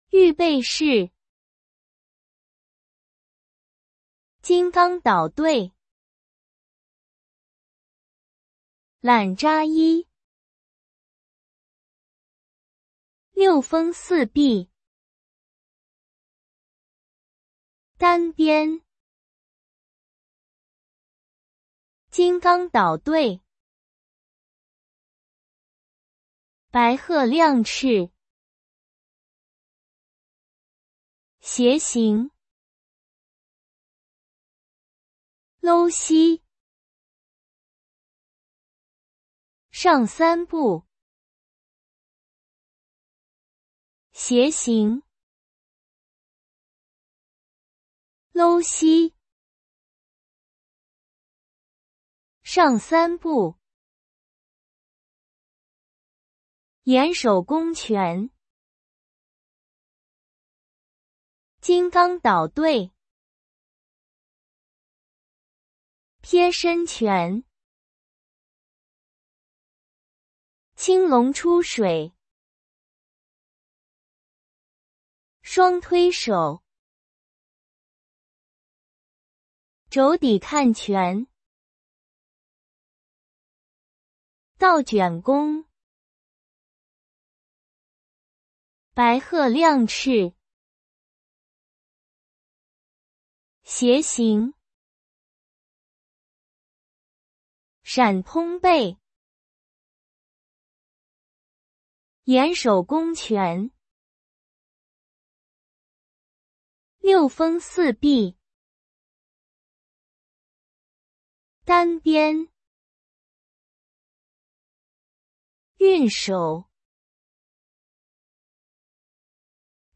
Per esercitarvi nella pronuncia, trovate qui l’audio MP3 con i nomi in cinese, pronunciati da un servizio di Google.